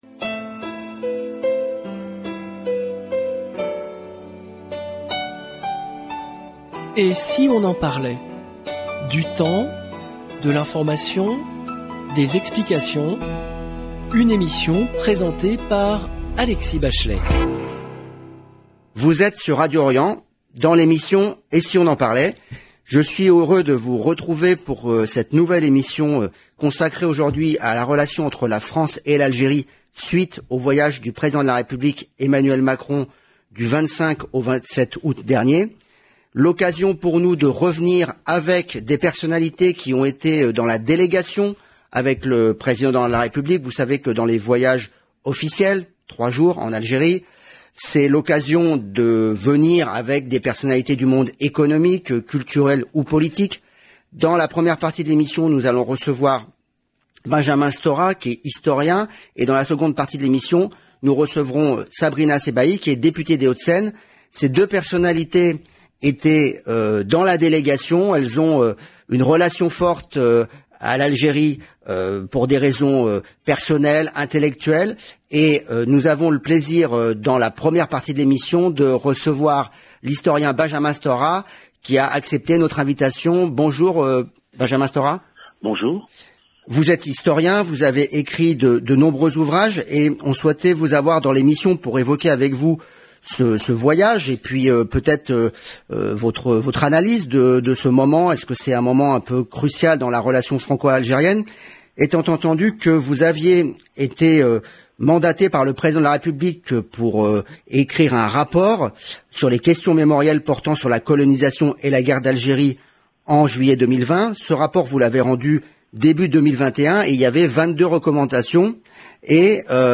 Benjamin Stora, historien et Sabrina Sebaihi, députée Nupes EELV des Hauts-de-Seine. 0:00 31 min 49 sec